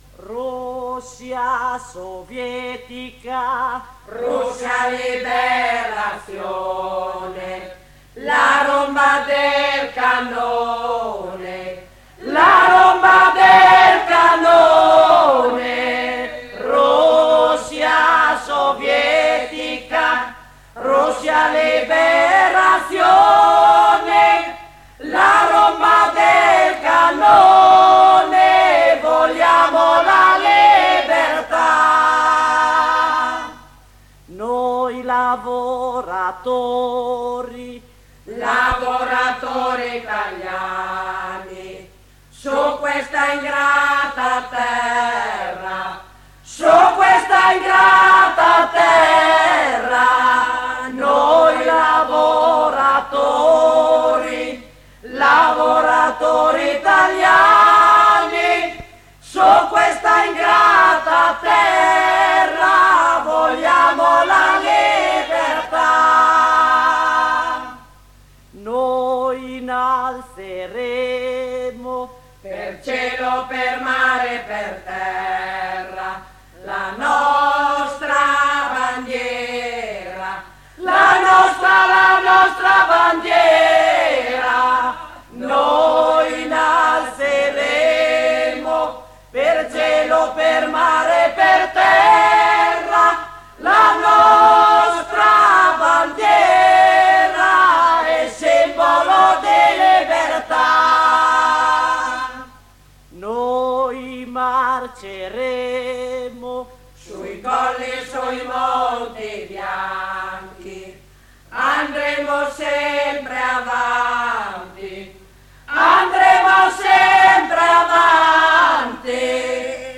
Registrazioni dal vivo e in studio, 1982 circa